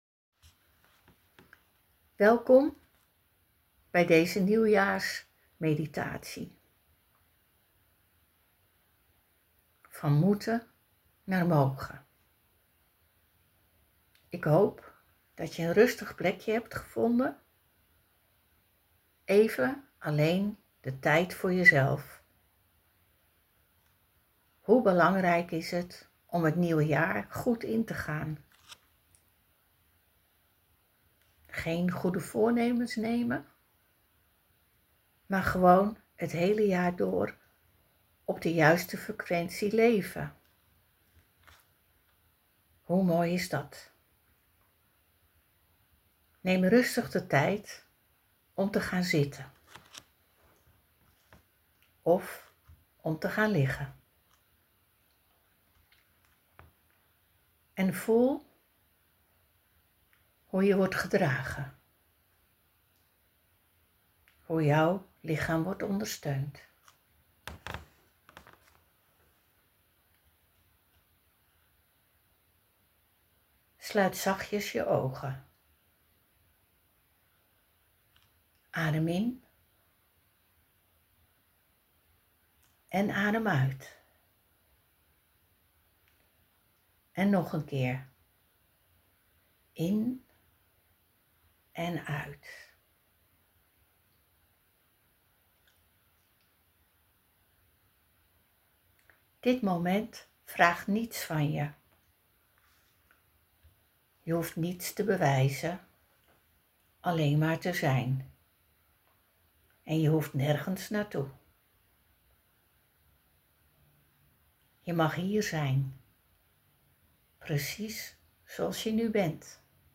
Maak kennis met mijn stem en ontspan direct: